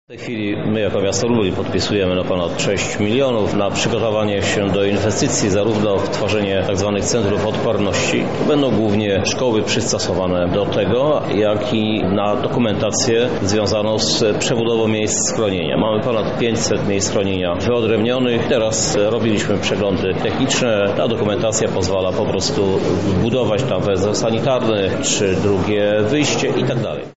O inwestycji dla naszego miasta mówi Krzysztof Żuk, prezydent Lublina.